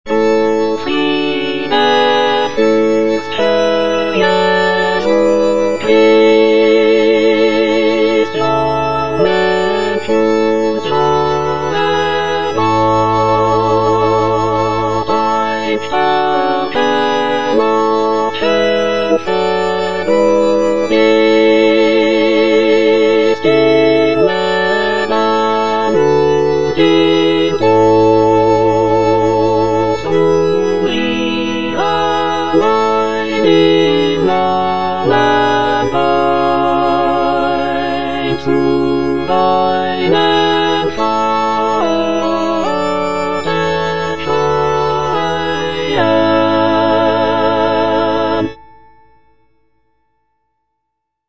Choralplayer playing Cantata
Alto (Emphasised voice and other voices) Ads stop